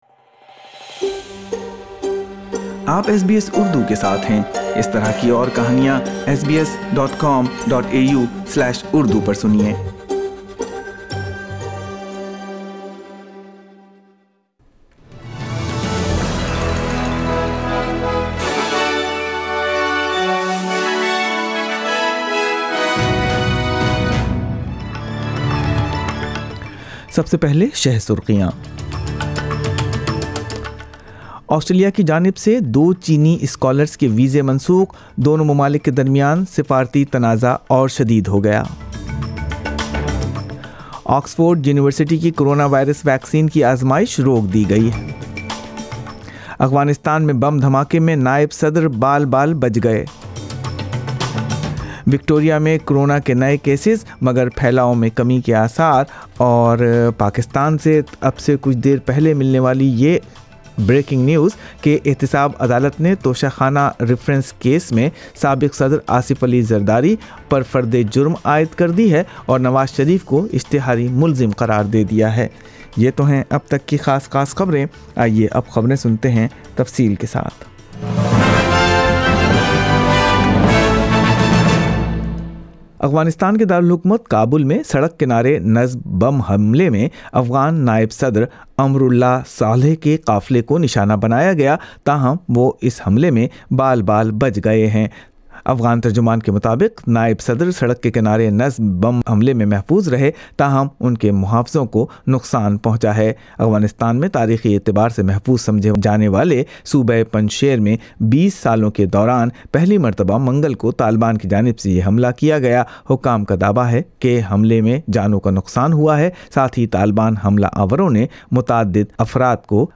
اردو خبریں بدھ 9 ستمبر 2020